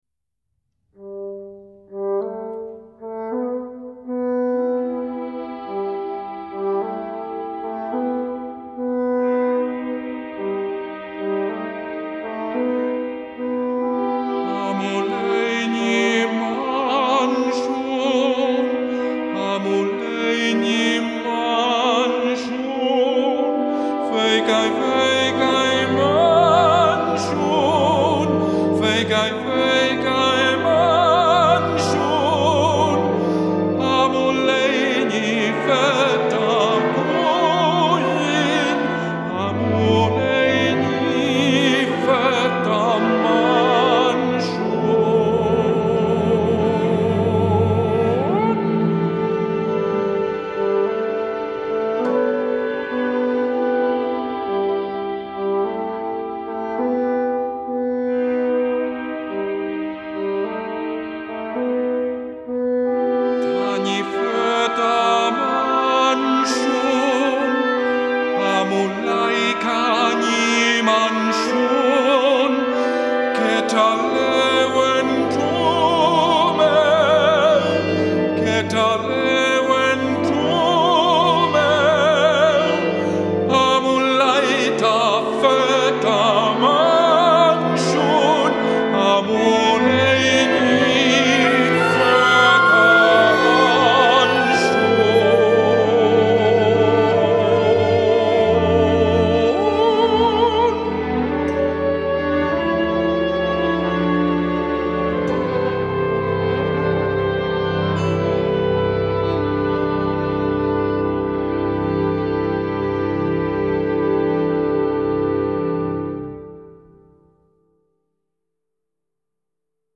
Orquesta
Música vocal